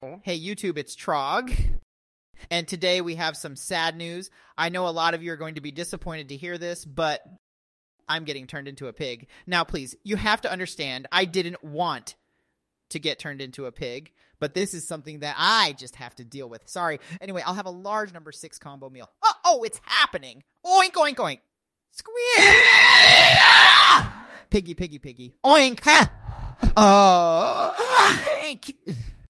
the harrowing sounds of a man being turned into a pig
trog_turns_into_a_pig.mp3